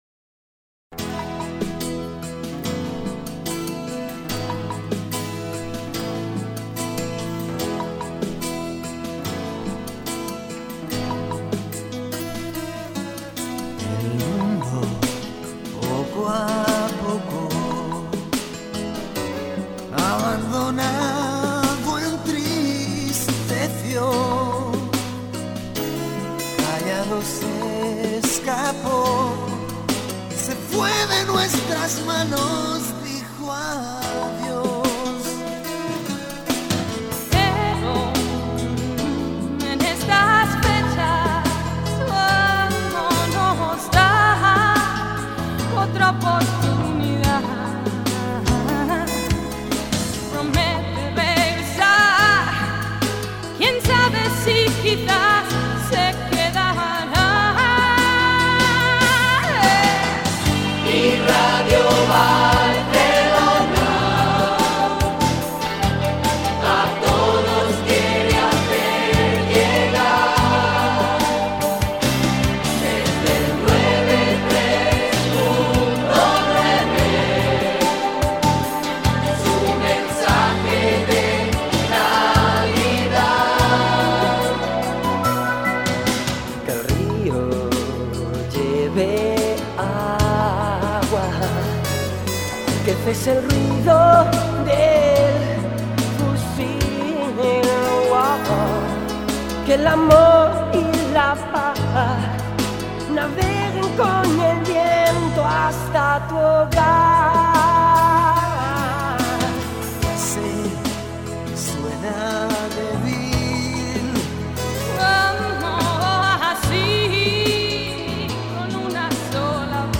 Nadala en la que participen als cors els locutors
amb les veus solistes